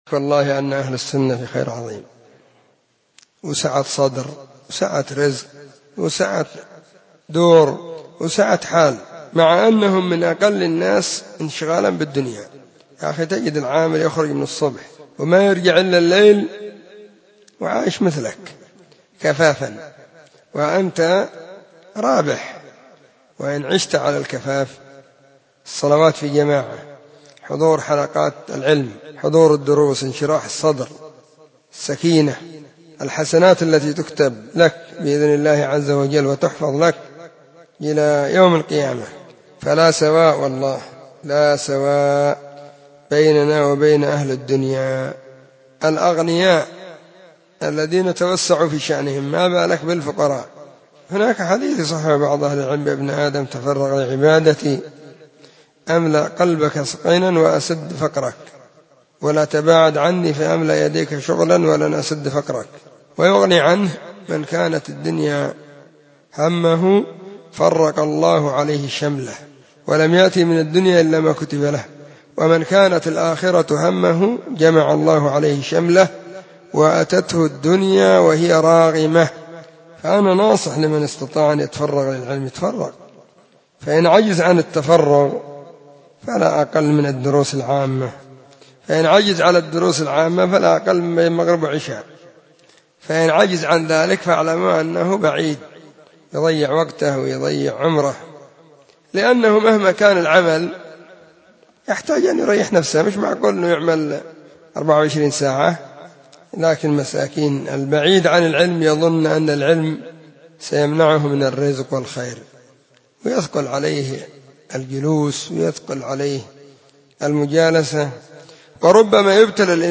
💢نصيحة قيمة بعنوان:لا سواء بيننا وبين أهل الدنيا*